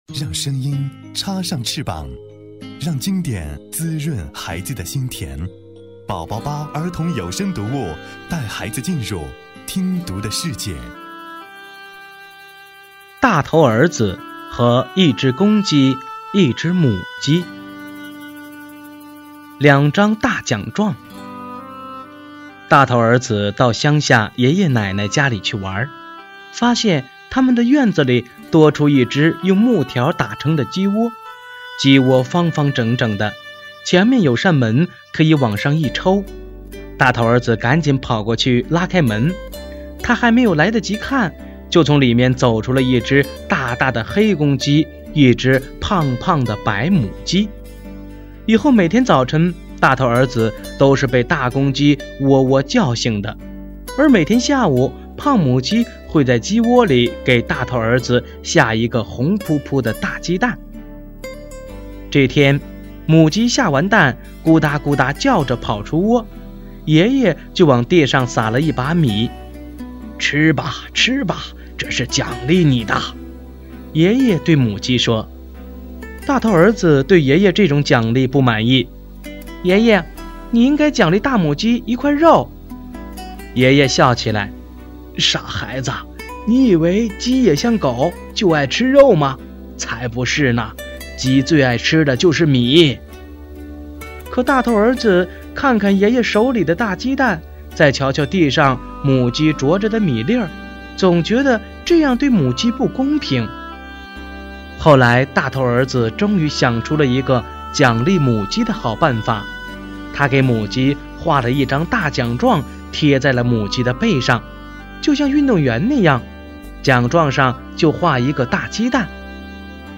首页>mp3 > 儿童故事 > 01两张大奖状（大头儿子和公鸡母鸡）